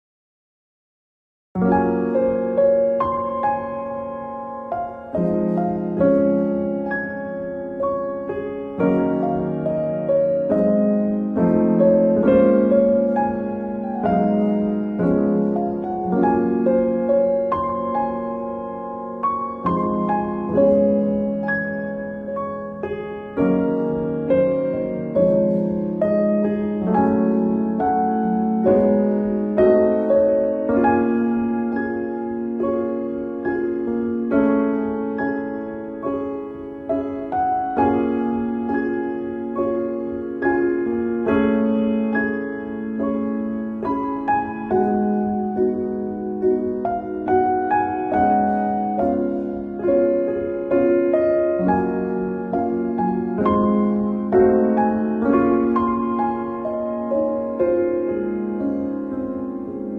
Treatment foots ASMR Part sound effects free download